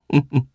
Ezekiel-Vox_Happy1_jp.wav